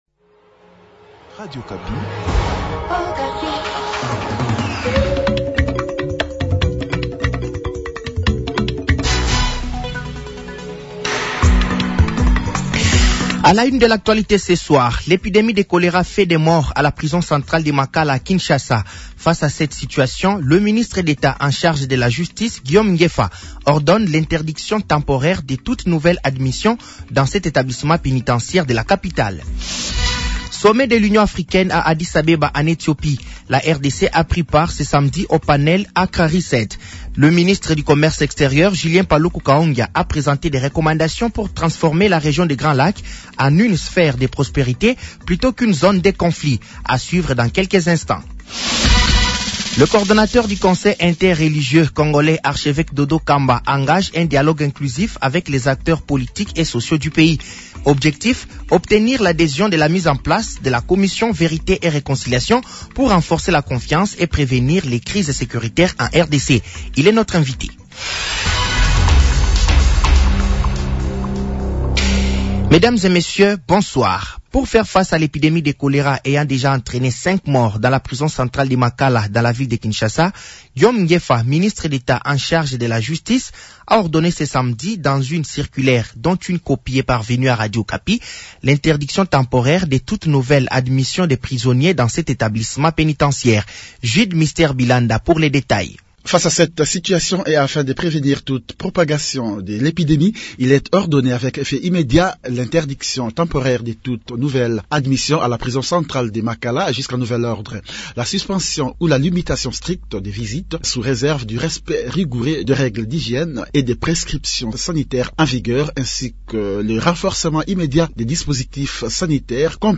Journal français de 18h de ce dimanche 15 février 2026